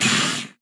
sounds / mob / phantom / hurt3.ogg
hurt3.ogg